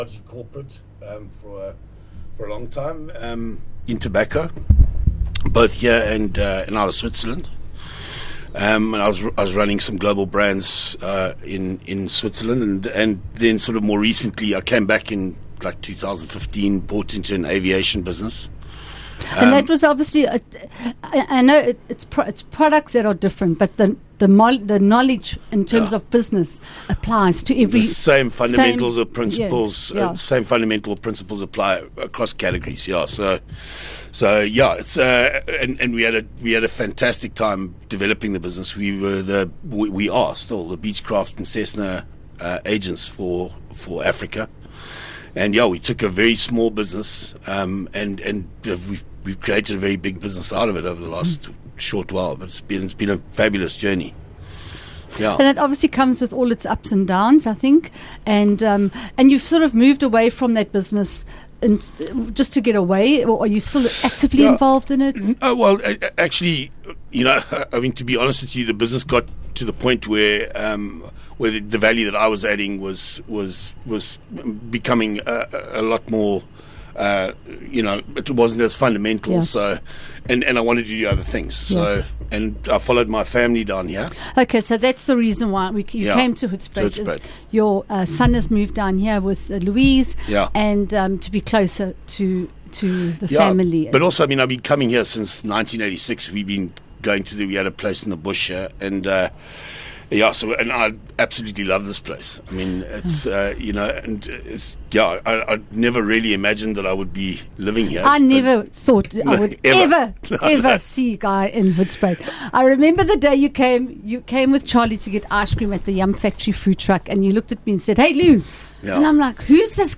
All talk interview , talking all things hoedspruit
Radio Interviews